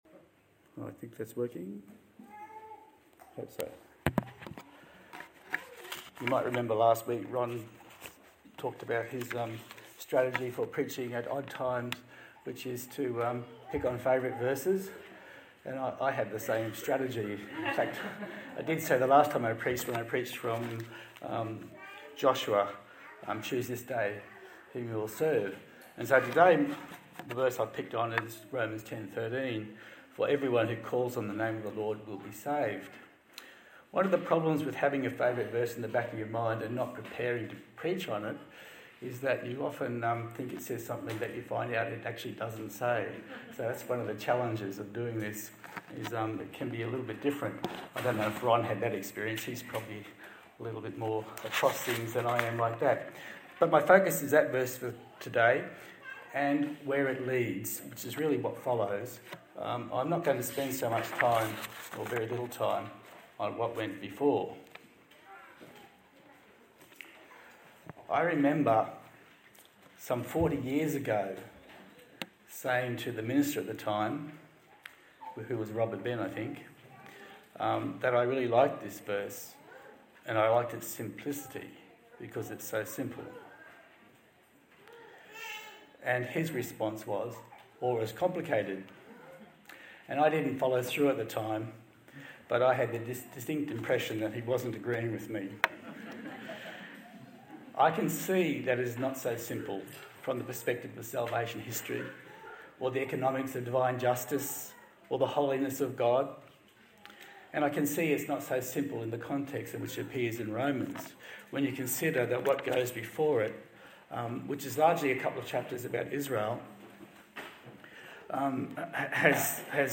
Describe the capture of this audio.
Service Type: Sunday Morning A sermon on the book of Romans